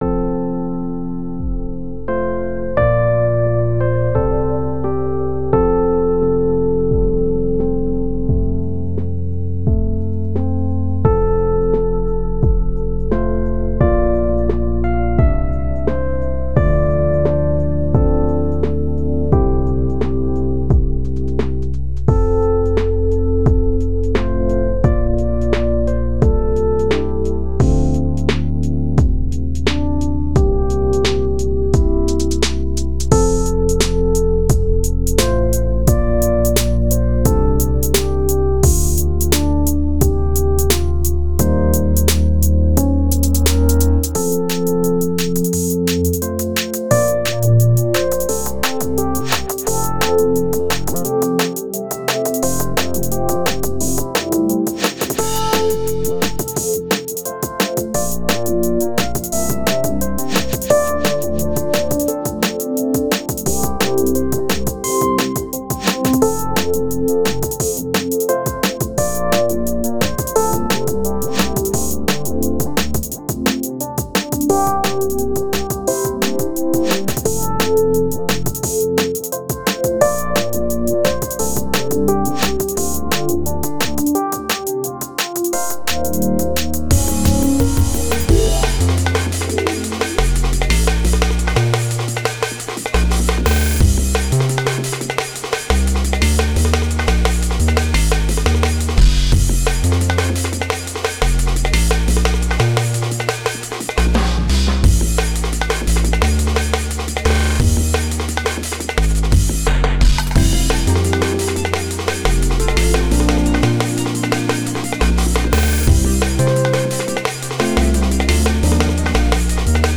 This song also has some built-in summer heat and humidity.